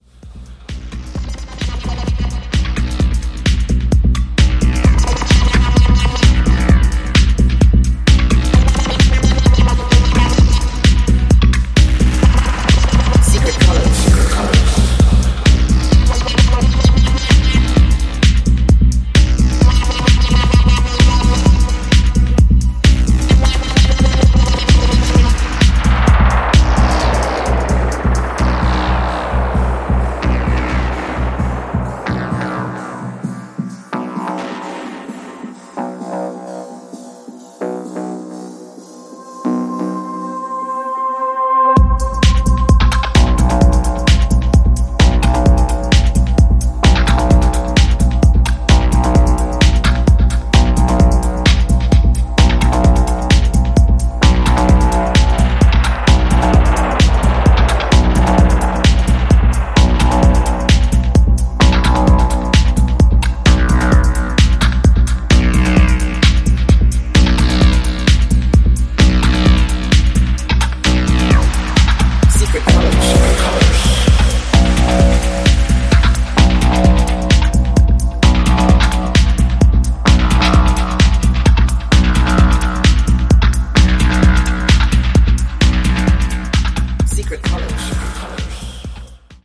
本作では、EBMやアシッド等の要素を盛り込んだダークでソリッドなエレクトロ・ハウスを展開しています。